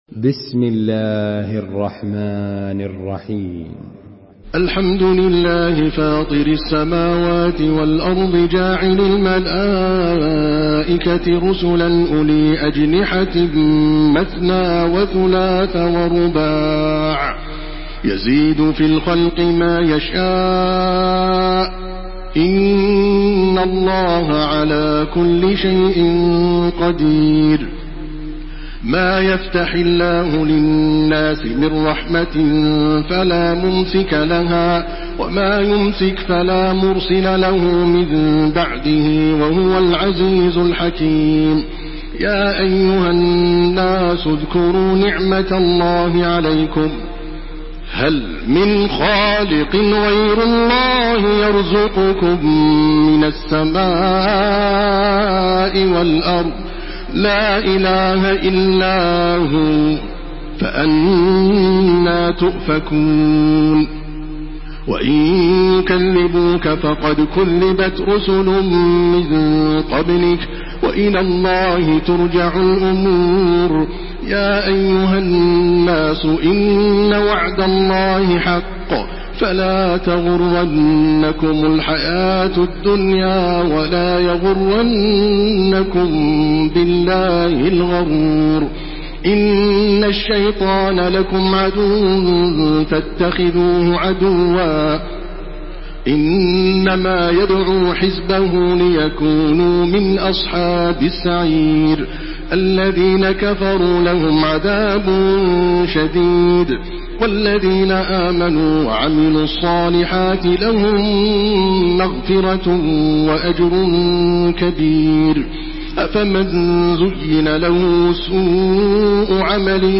Listen and download the full recitation in MP3 format via direct and fast links in multiple qualities to your mobile phone.
Makkah Taraweeh 1429
Murattal